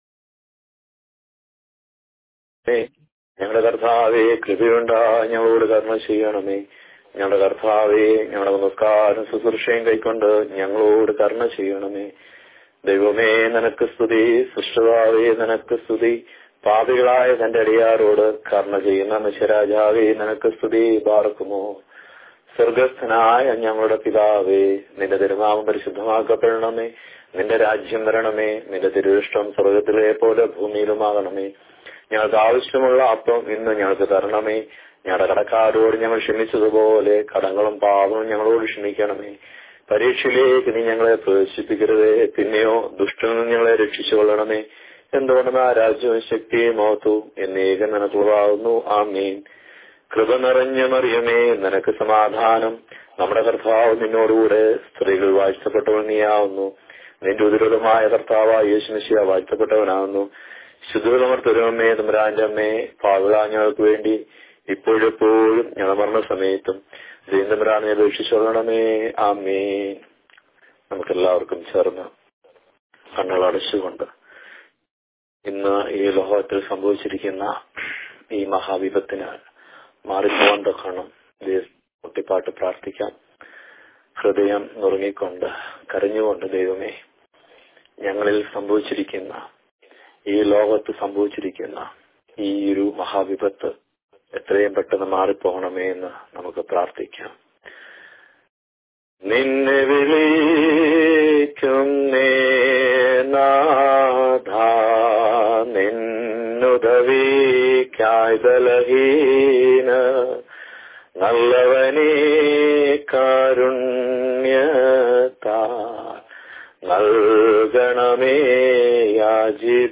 Bible Class Recordings
Because of the vast geographical area covered by the diocese, it was natural to set up a zoom session to achieve the purpose of the Bible study.